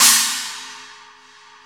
Index of /90_sSampleCDs/AMG - Now CD-ROM (Roland)/DRM_NOW! Drums/NOW_K.L.B. Kit 1